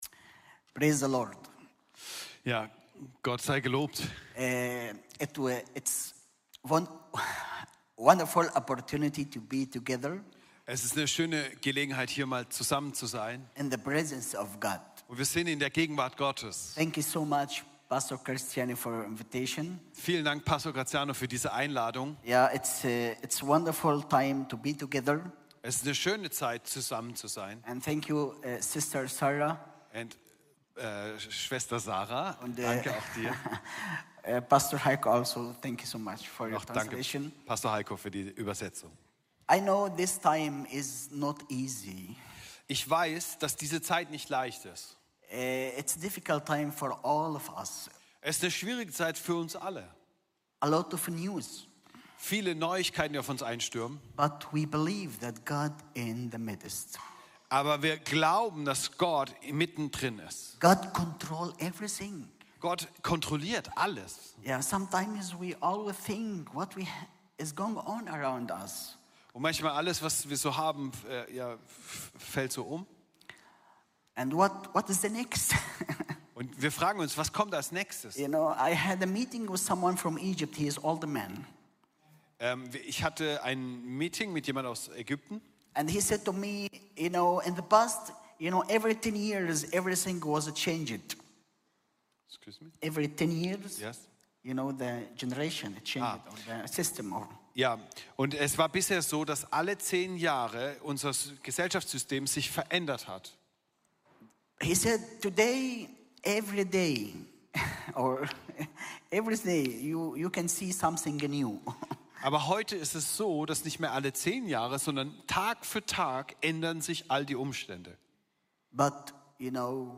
Dienstart: Sonntag